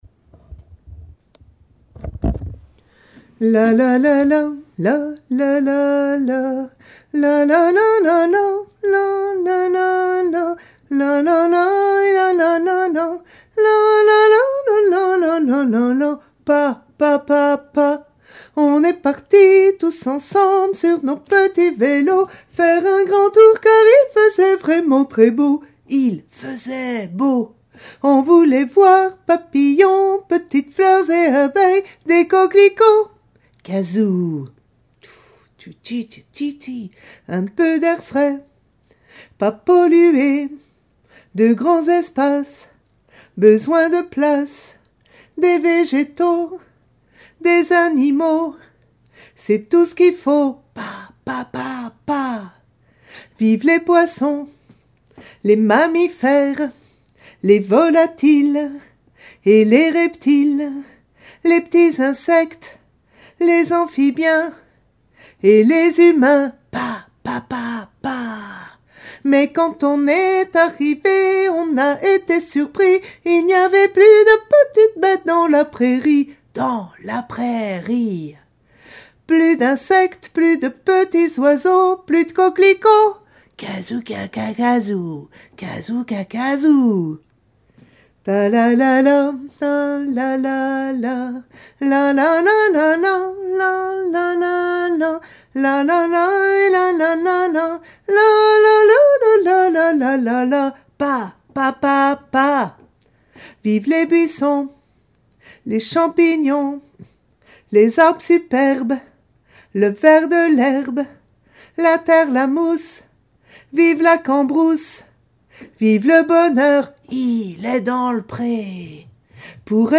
Toutes les voix en une seule version